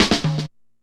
Drum Roll.wav